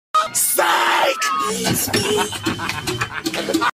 Tyler The Creator SIKE meme sound effects free download